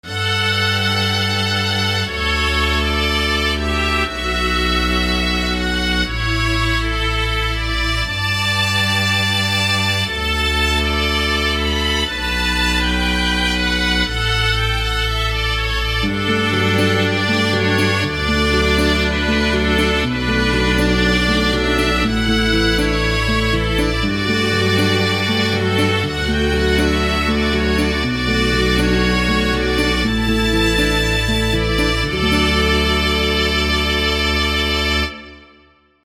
Violin Orchestral Sample
I just tried if I can do orchestral sounds using FL Studio, of course^^. . The guitar did light a nice touch with the strings.
Music / Classical